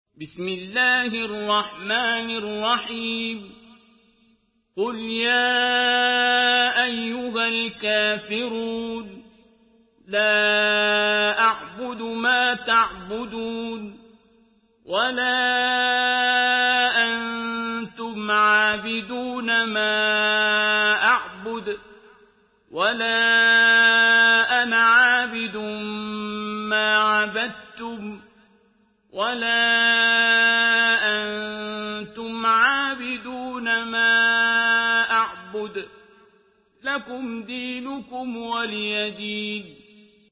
Surah আল-কাফিরূন MP3 in the Voice of Abdul Basit Abd Alsamad in Hafs Narration
Surah আল-কাফিরূন MP3 by Abdul Basit Abd Alsamad in Hafs An Asim narration.
Murattal Hafs An Asim